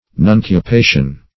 Nuncupation \Nun`cu*pa"tion\, n.